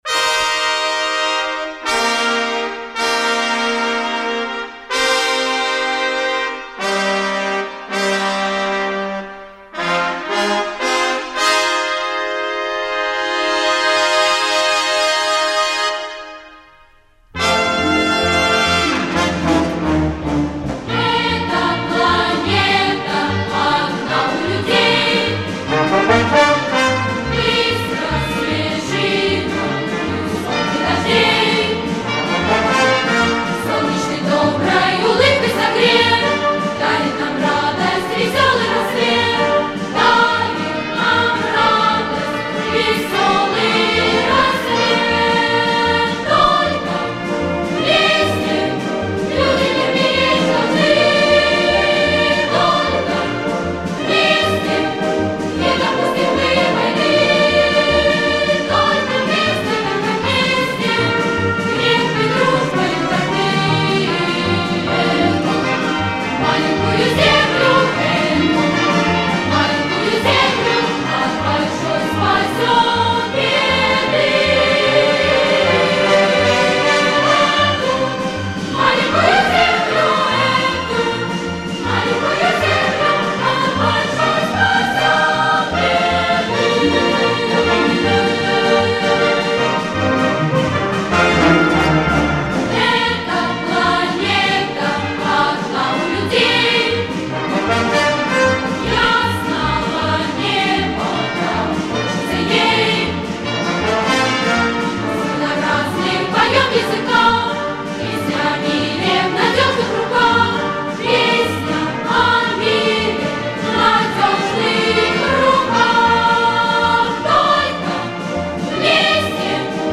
Звуковая дорожка видеоклипа